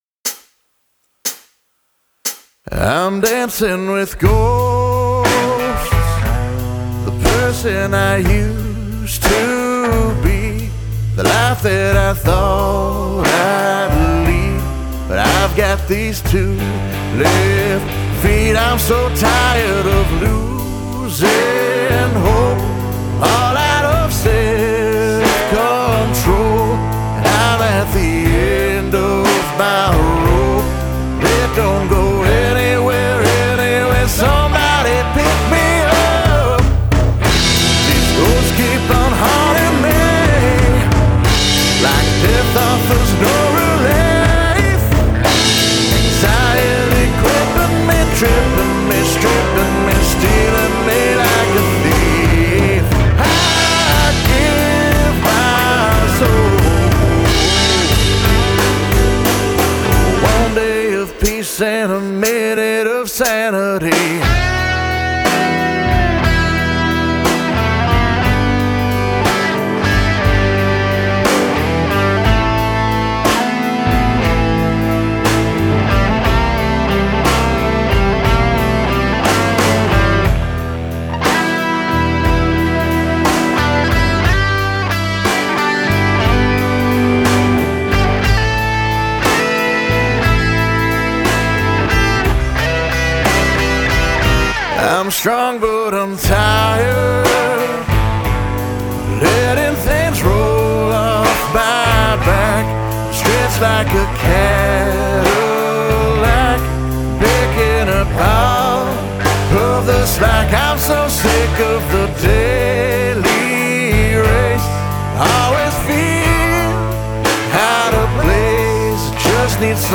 Vocals, guitars, bass, keys
Drums
Additional guitar arrangement